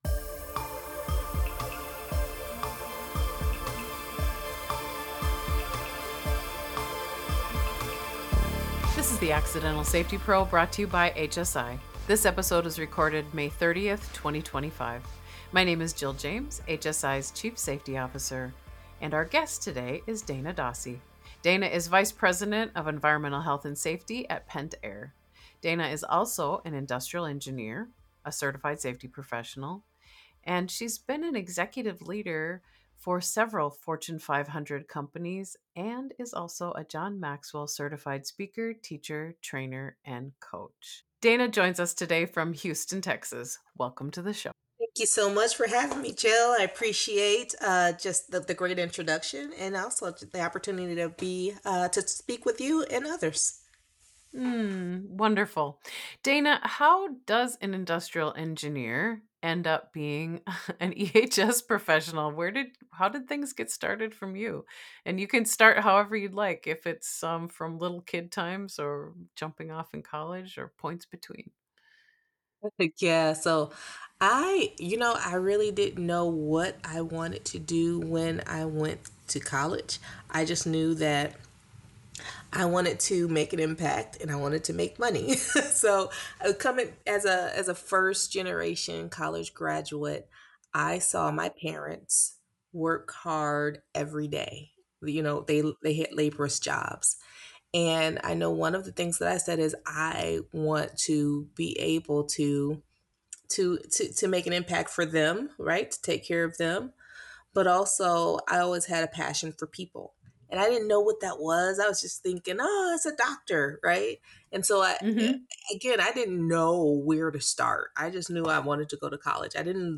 This safety podcast is a series of conversations with safety professionals about how they came into their role, what they've learned along the way, as well as some of the highs and lows that come with job.